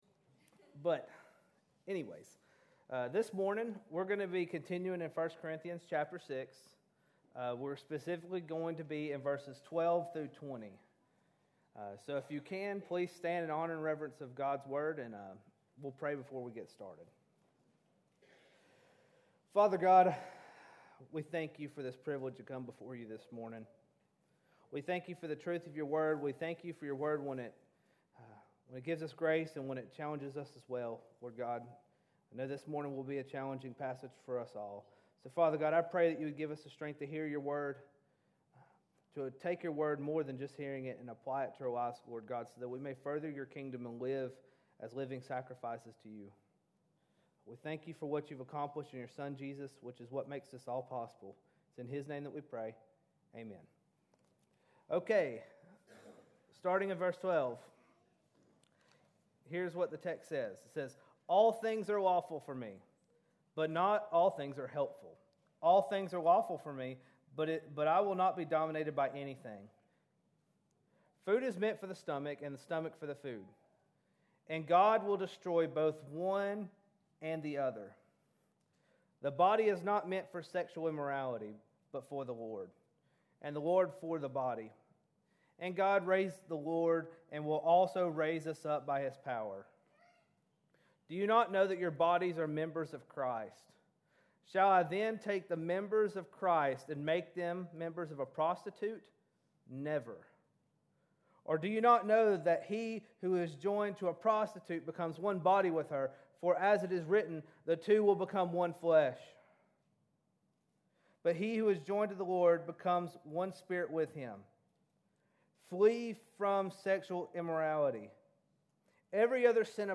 In this sermon, we dive into 1 Corinthians 6:12-20 to learn what Paul has to say on the matter of sexual immorality.